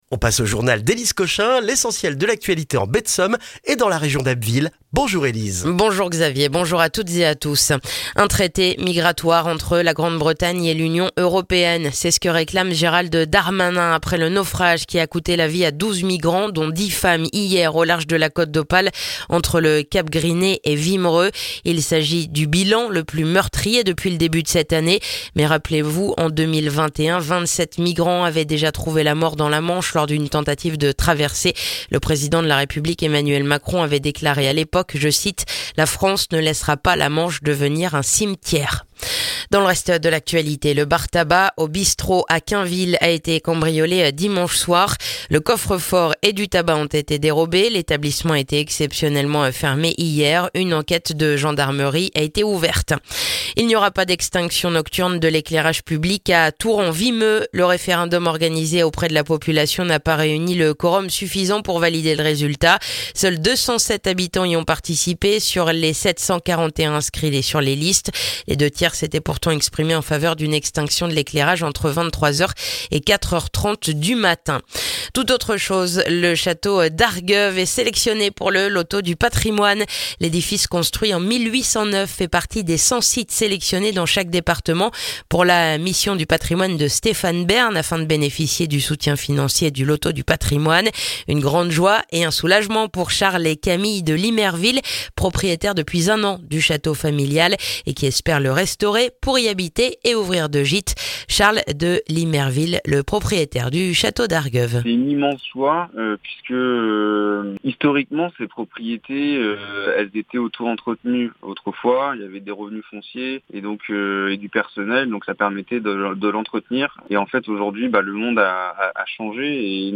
Le journal du mercredi 4 septembre en Baie de Somme et dans la région d'Abbeville